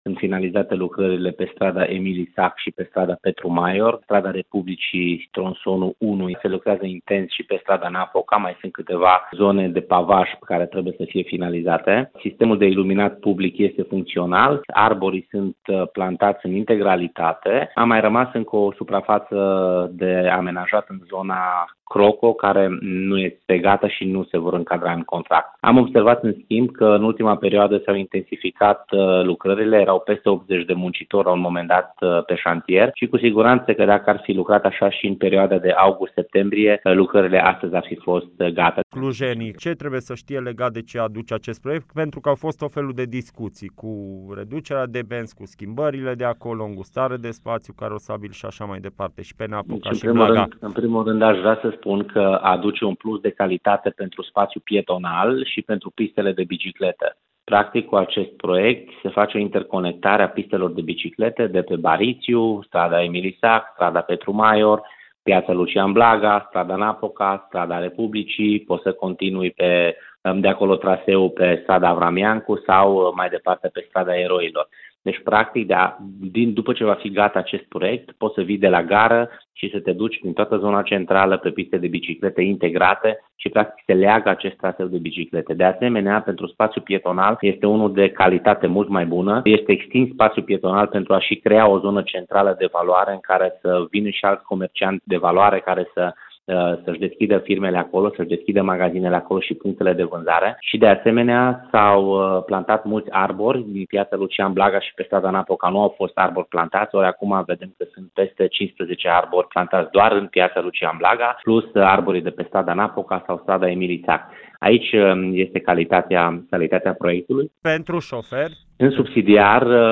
Constructorul nu a reușit să termine încă lucrările, dar proiectul va fi gata în prima parte a lunii decembrie, a declarat pentru EBS Radio viceprimarul Dan Tarcea.
Mini-interviu-Tarcea.mp3